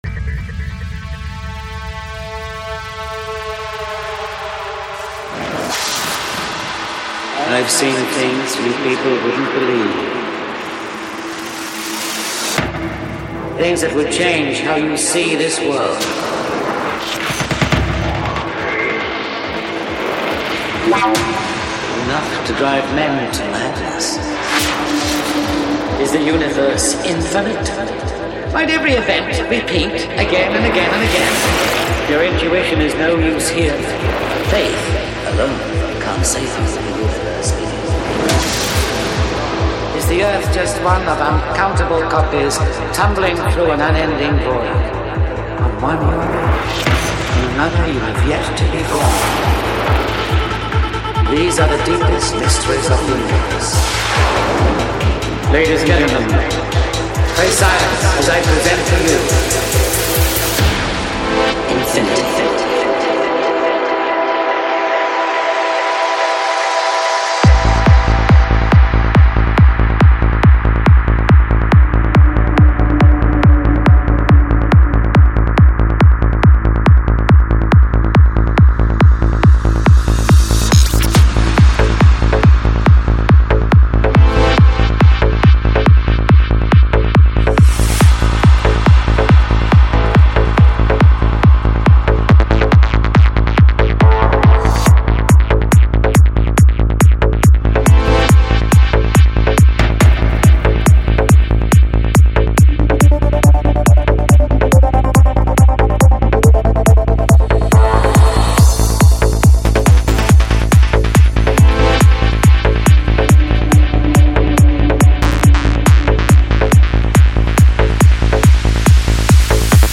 Жанр: Trance
Альбом: Psy-Trance